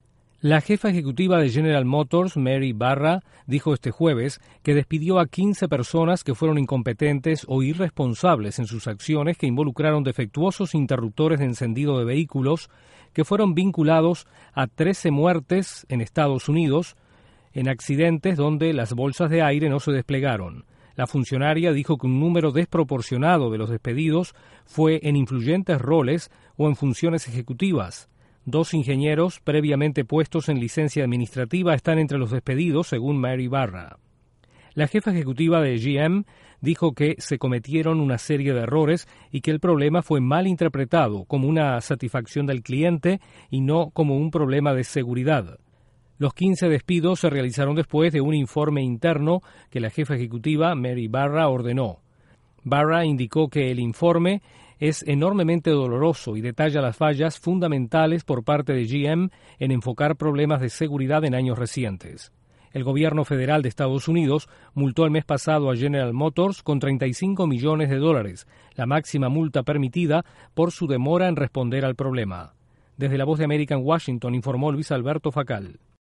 La compañía automotriz estadounidense General Motors despide a 15 influyentes funcionarios por controversia sobre llamado a reparación de vehículos. Desde la Voz de América en Washington informa